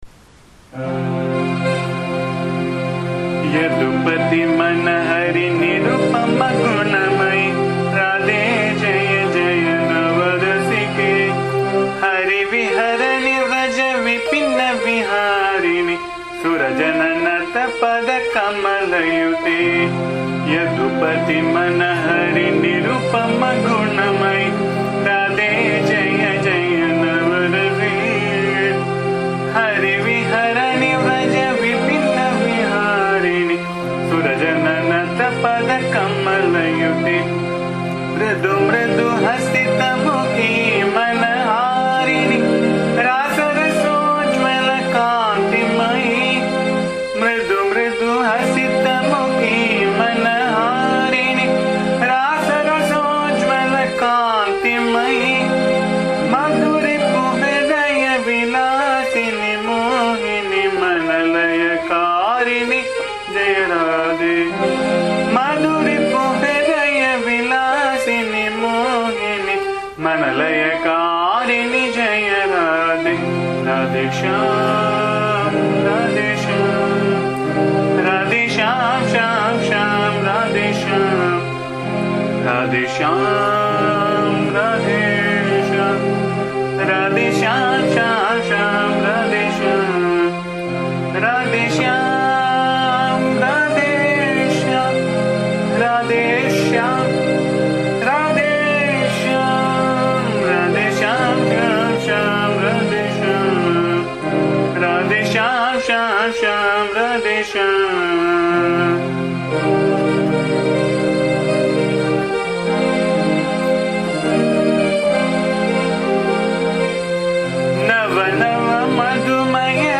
The song sung in my voice can be found here
bhajan song